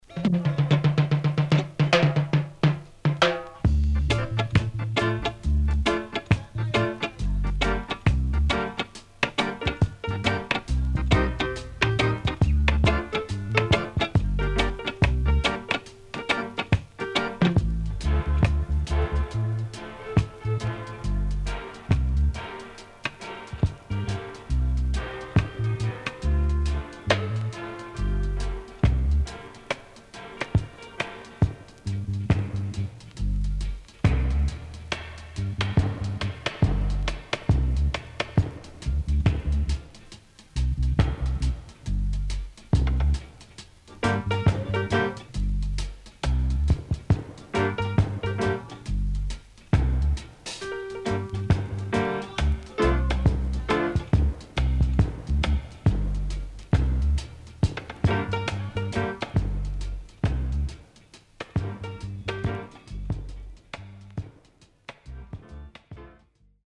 Killer Roots & Dubwise
SIDE A:薄いヒスノイズあります。